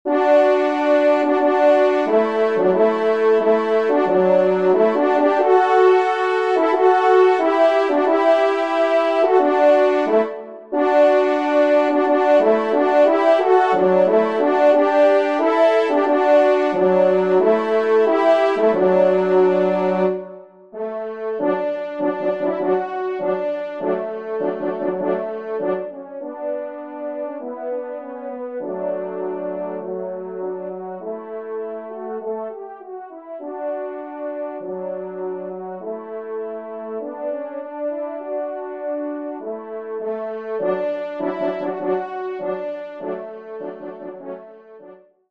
Pupitre 3° Cor  (en exergue)